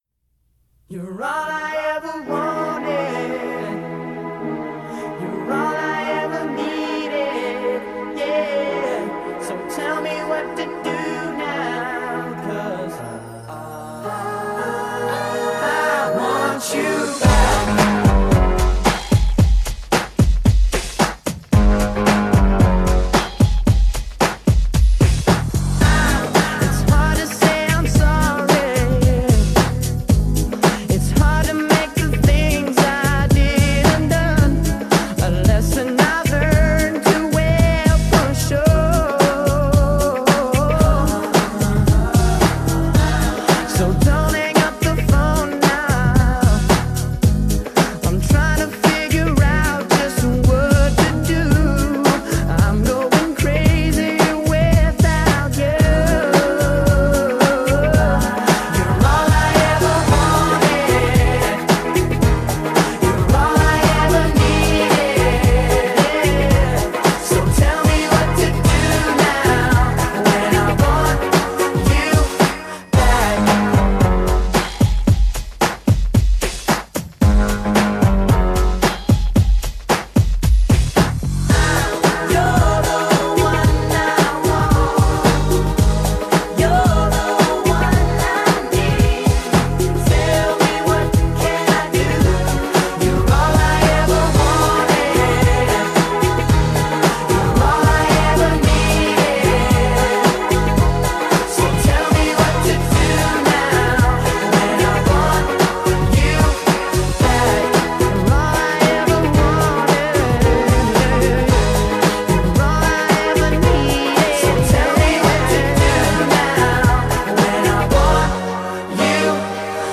BPM112
MP3 QualityMusic Cut